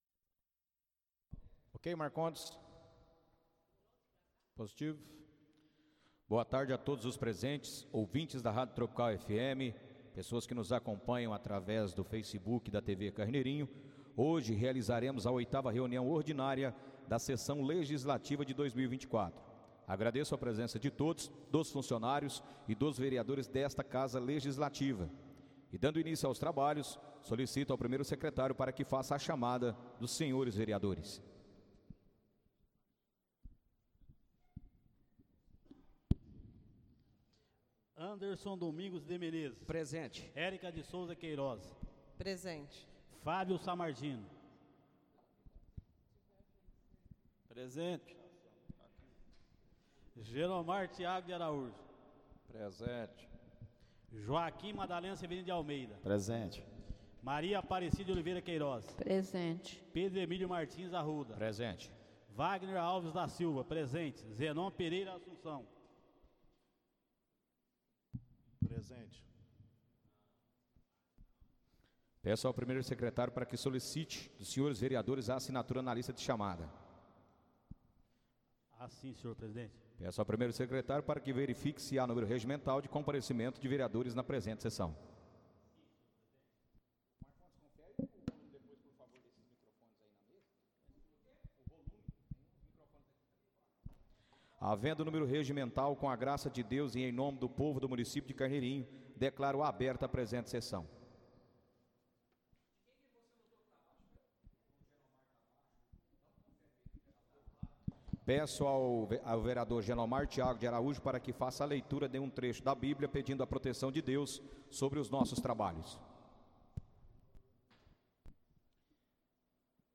Áudio da 8.ª reunião ordinária de 2024, realizada no dia 20 de Maio de 2024, na sala de sessões da Câmara Municipal de Carneirinho, Estado de Minas Gerais.